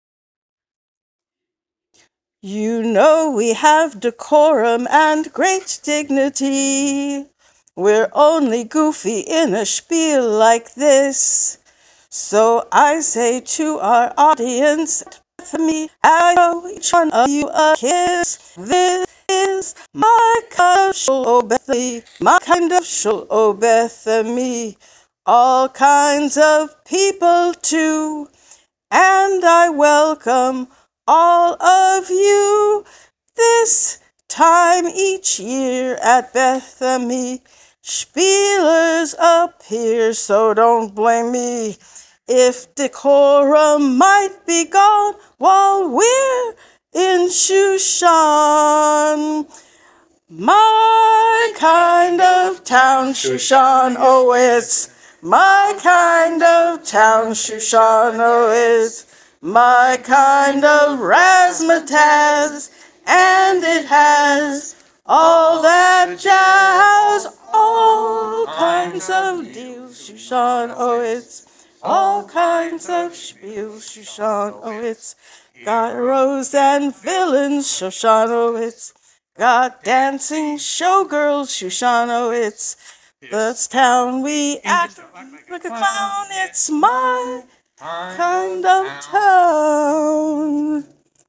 Rough recordings.
Tempos on some things could get faster when we are more familiar.